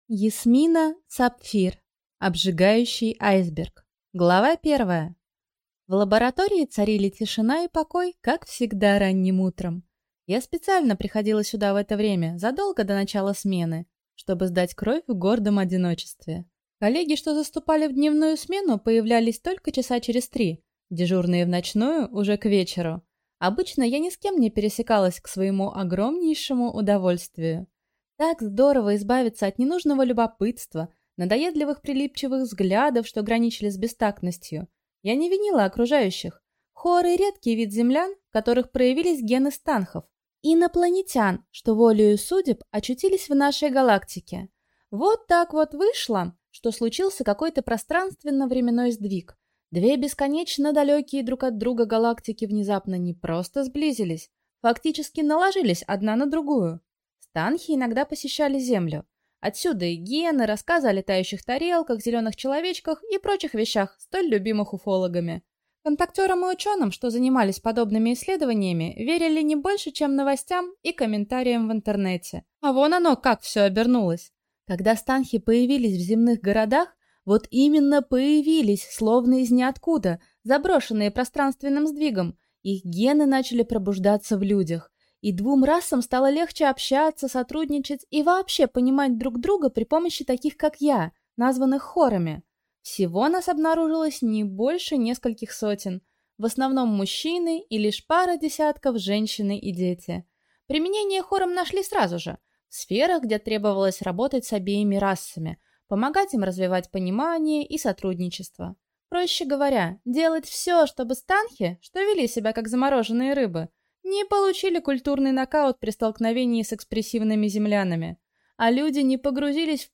Аудиокнига Обжигающий айсберг | Библиотека аудиокниг